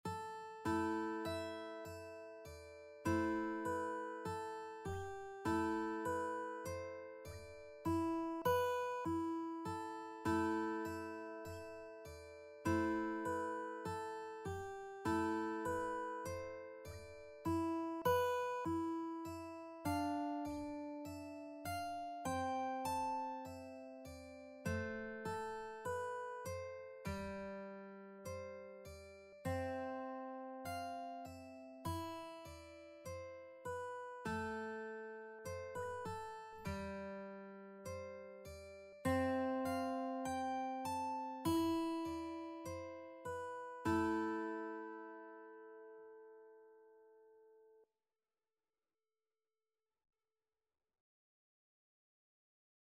The melody is in the minor mode.
4/4 (View more 4/4 Music)
Mandolin  (View more Easy Mandolin Music)